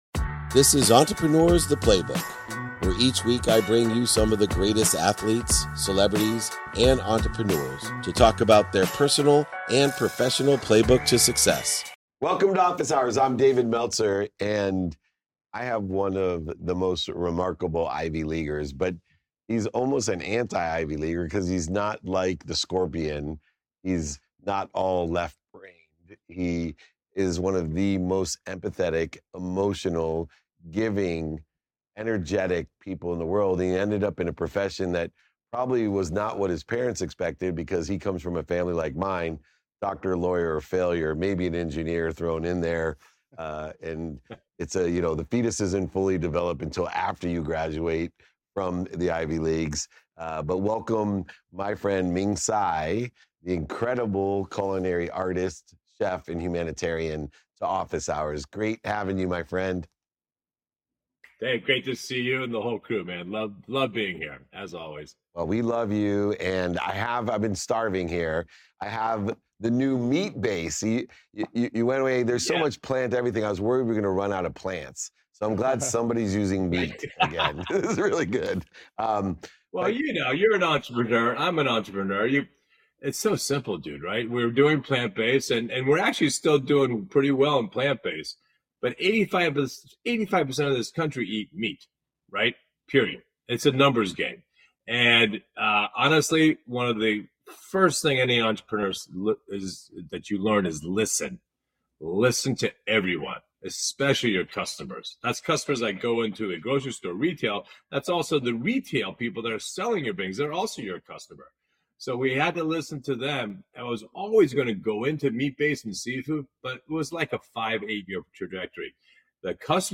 In today's episode, I’m joined by renowned chef and television personality, Ming Tsai, who is best known for his innovative East-West fusion cuisine. We explore Ming's journey from an Ivy League education to becoming a culinary icon, embracing plant-based cooking without abandoning meat, and his commitment to 'do good' through his business. Ming shares how his unique approach to food reflects his belief that eating well can also mean doing good, with a portion of his profits supporting cancer research and family aid.